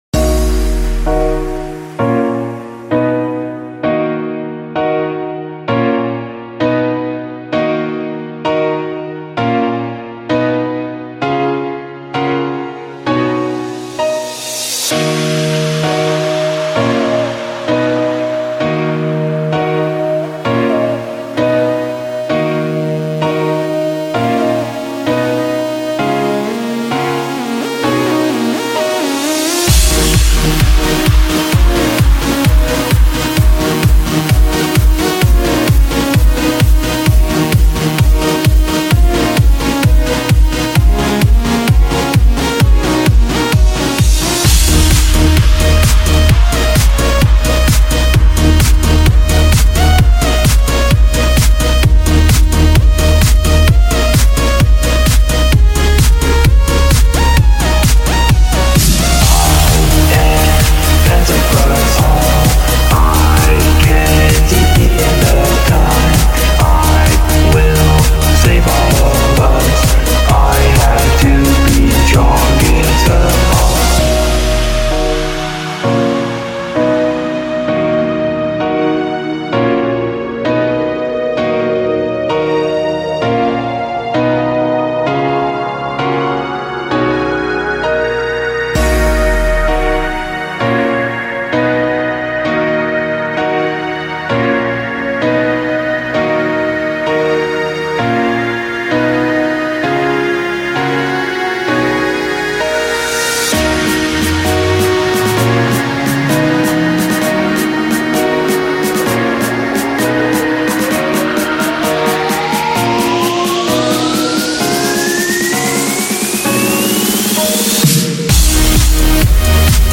The BPM is 130 Lyrics: I, Am, Best of us all.